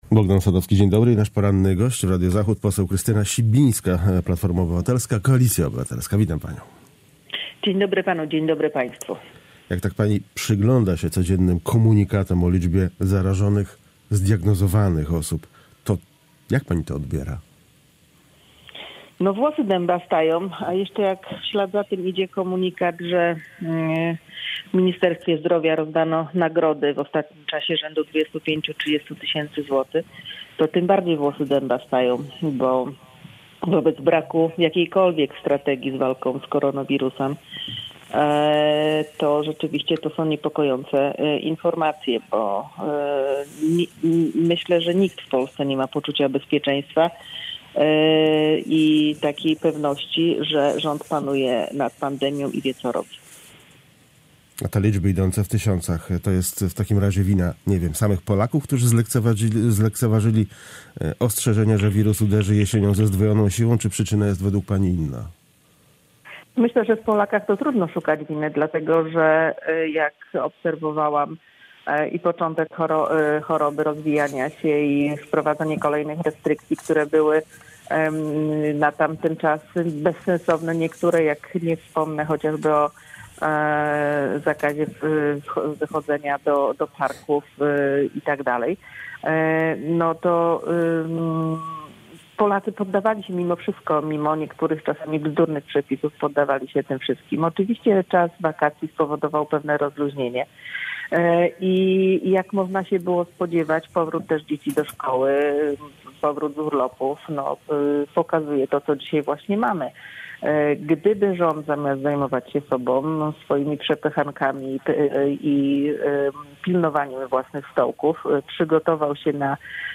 Z poseł Platformy Obywatelskiej rozmawiał